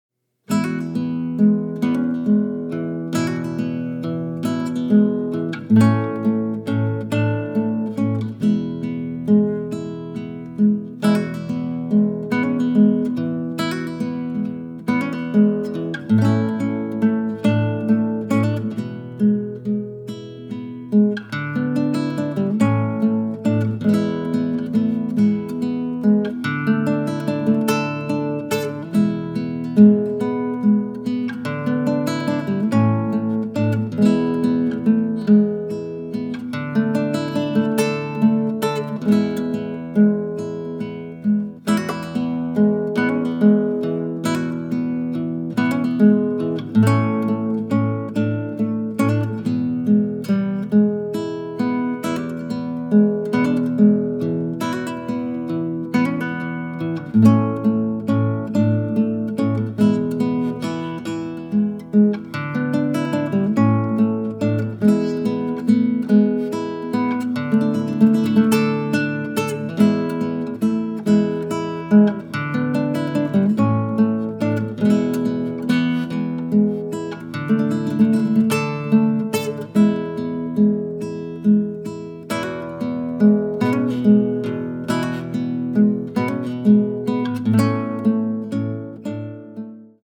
ingioiellano l'architettura musicale di un blues viscerale
banjo di ultima generazione